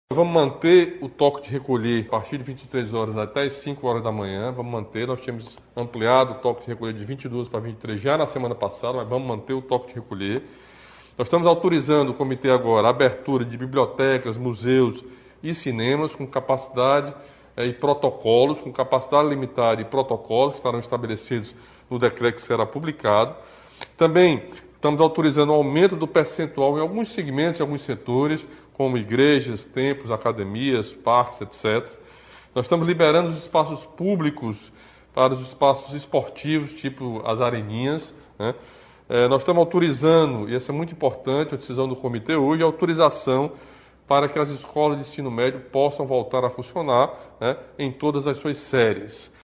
O governador Camilo Santana anunciou o novo decreto.